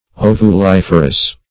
Ovuliferous \O`vu*lif"er*ous\, a.
ovuliferous.mp3